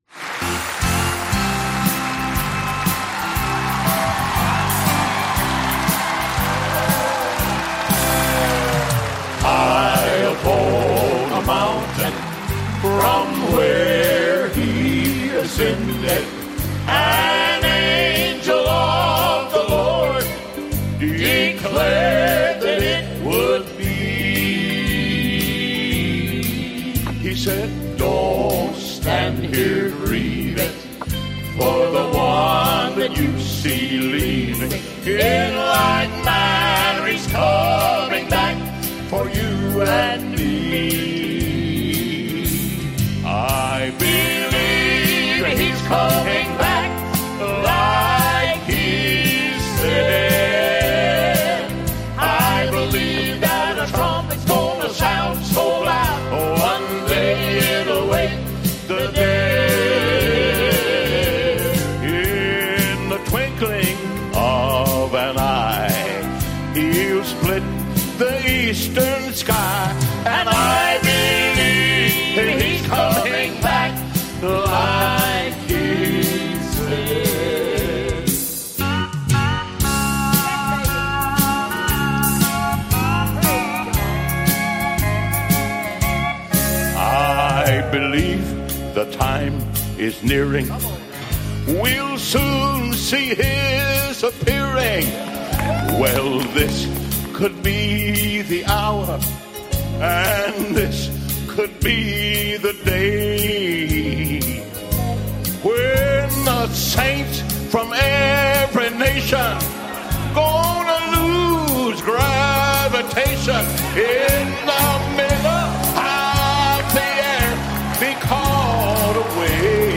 Saturday Night Livestream: Outpouring of the Holy Spirit
The Outpouring of the Holy Spirit: Teaching and prayer
I will also teach on the history of the great revivals in America and show the trigger for these revivals was intense prayer. There will also be prayer after the teaching.